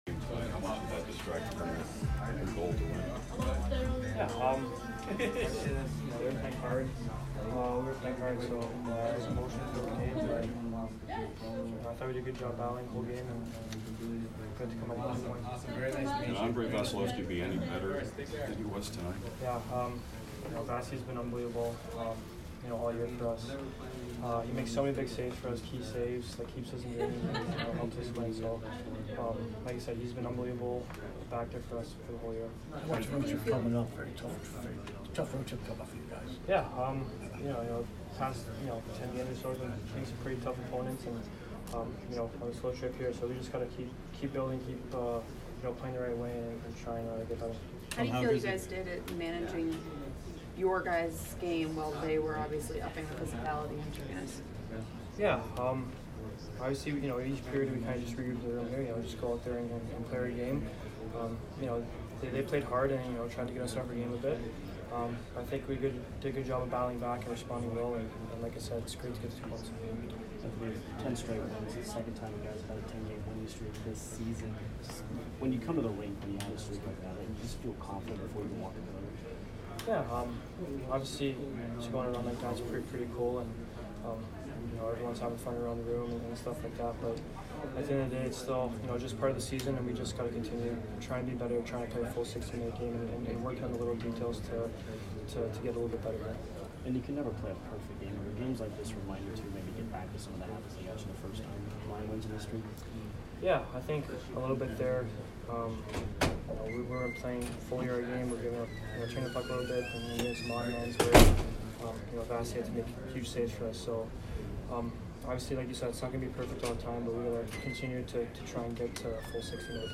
Anthony Cirelli post-game 2/15